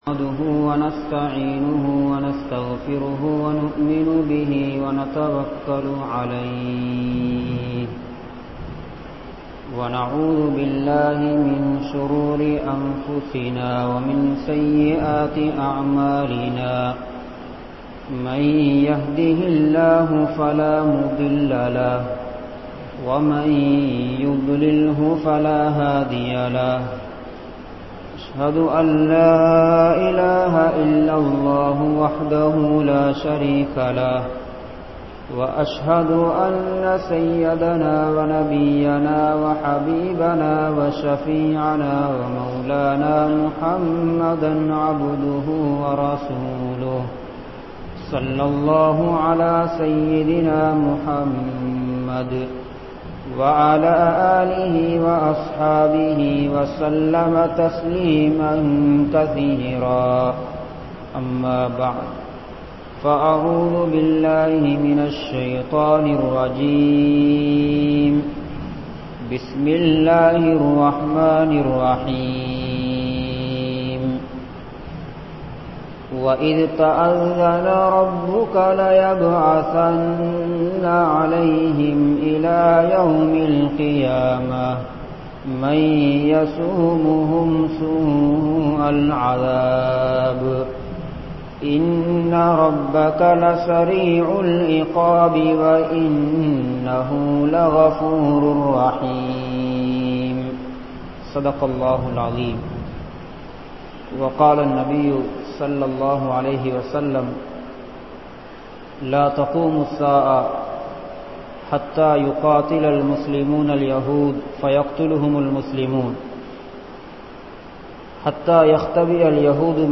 Muslim Entraal Yaar? | Audio Bayans | All Ceylon Muslim Youth Community | Addalaichenai